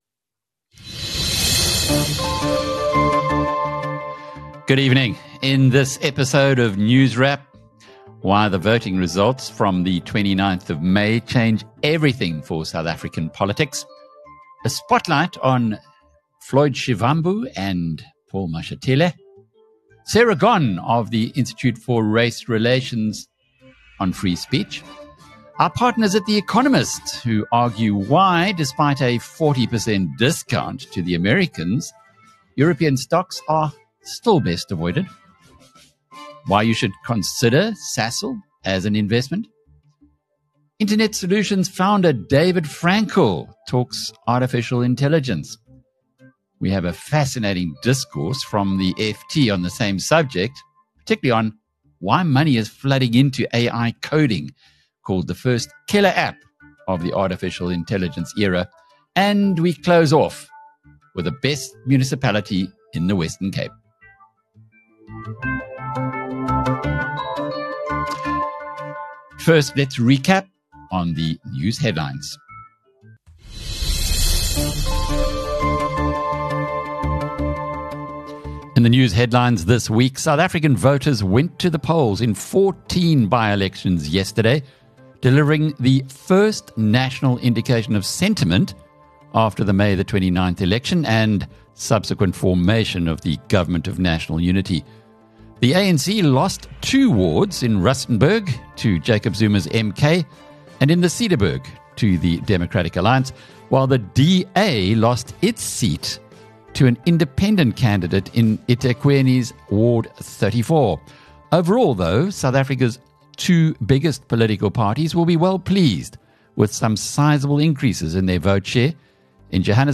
A bumper episode features a spread of news on the political economy and business, seeded with developments on AI and the free speech debate - plus a cameo with a windswept mayor of the best municipality in the Western Cape. This weekly show is produced by the BizNews team, and hosted by editor Alec Hogg.